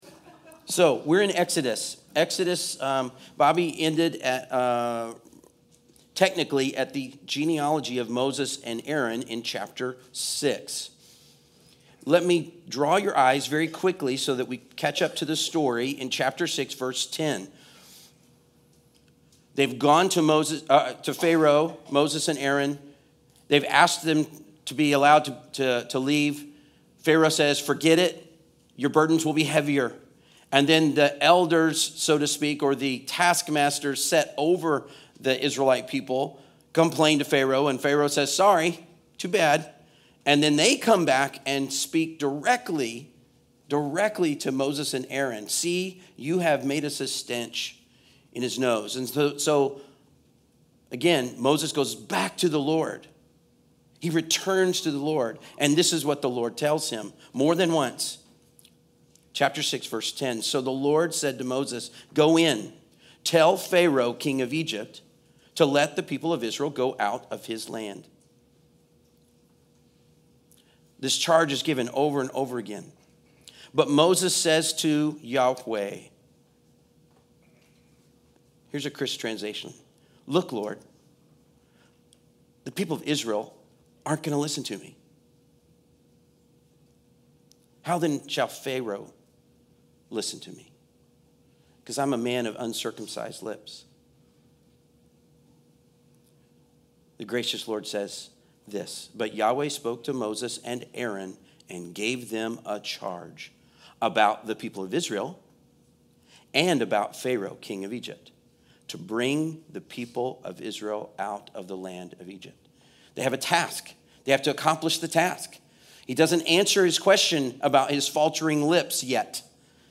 Passage: Exodus 6:28-8:15 Service Type: Sunday Service